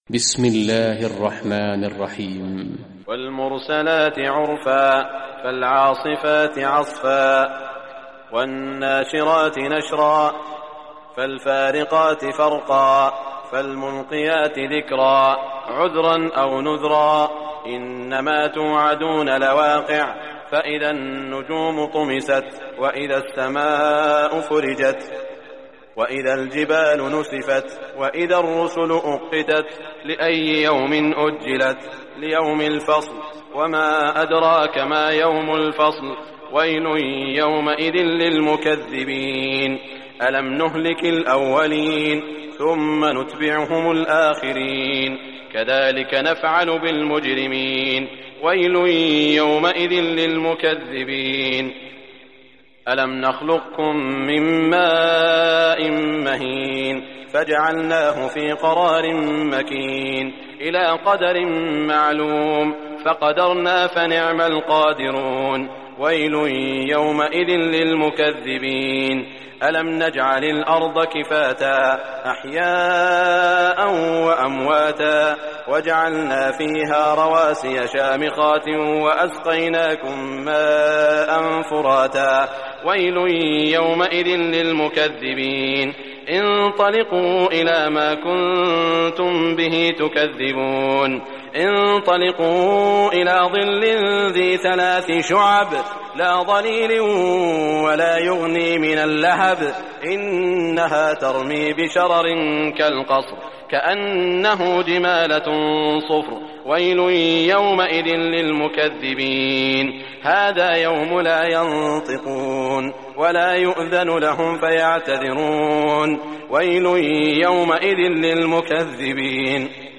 Surah Mursalat Recitation by Sheikh Shuraim
Surah Mursalat, listen or play online mp3 tilawat / recitation in Arabic in the beautiful voice of Sheikh Saud al Shuraim.